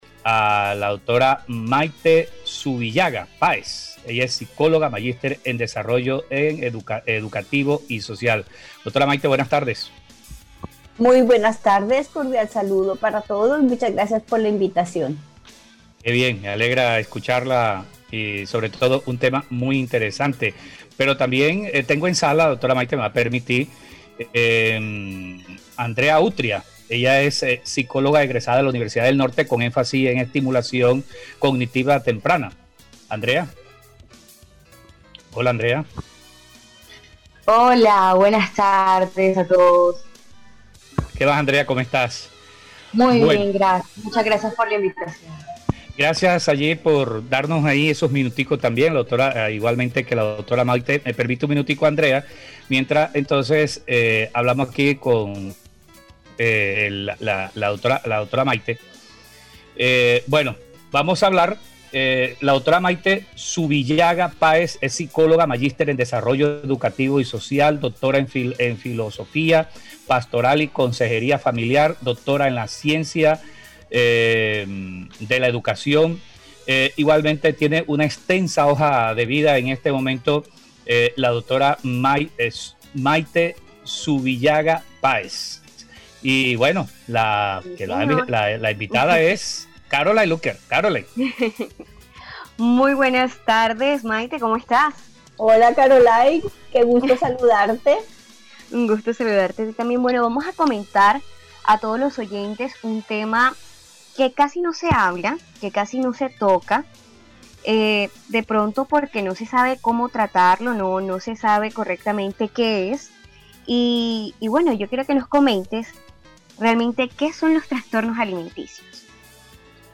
En entrevista con el programa Cuarentena